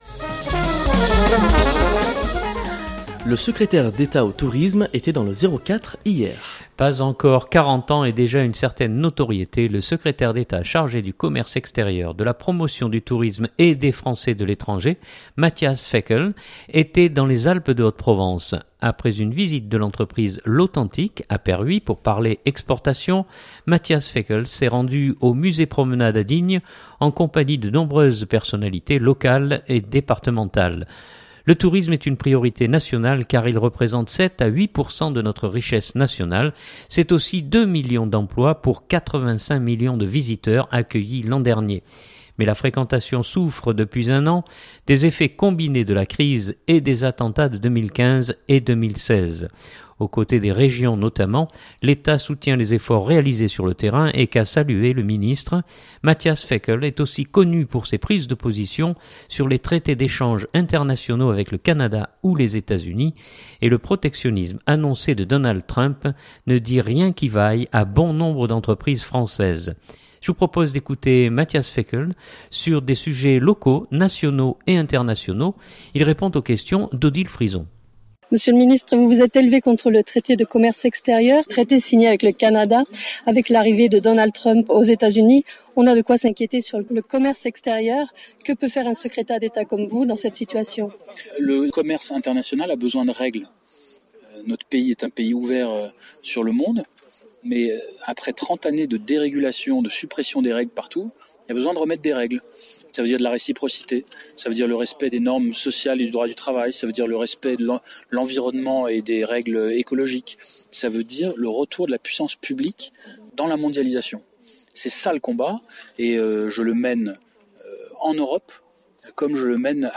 Je vous propose d’écouter Matthias Fekl sur des sujets locaux, nationaux et internationaux.